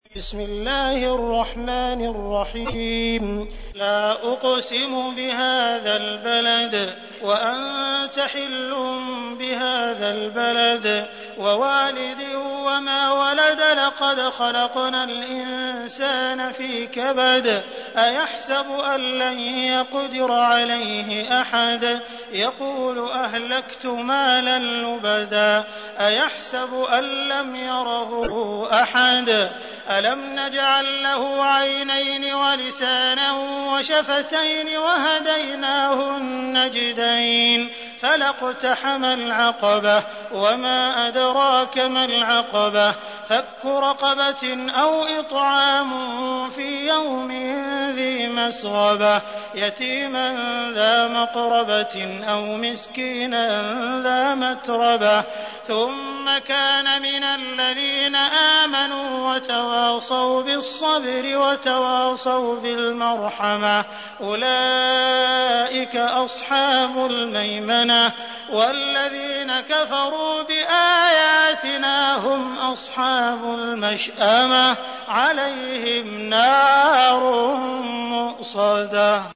تحميل : 90. سورة البلد / القارئ عبد الرحمن السديس / القرآن الكريم / موقع يا حسين